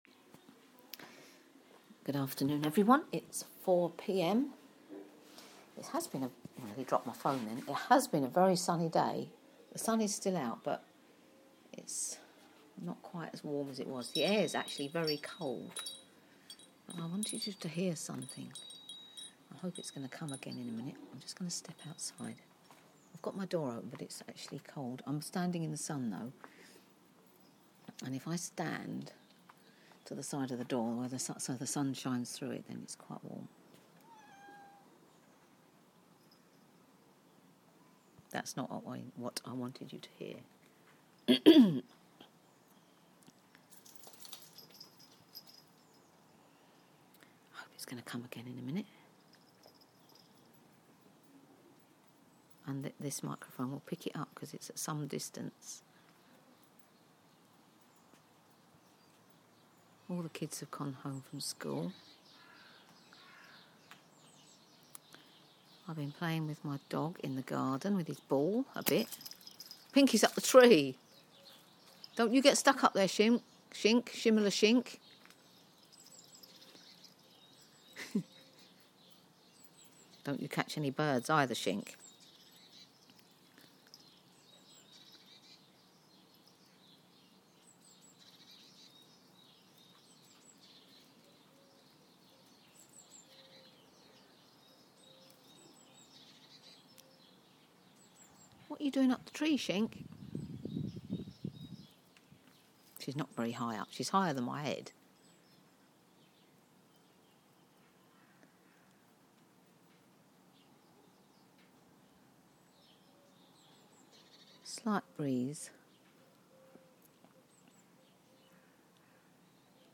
Some sounds of spring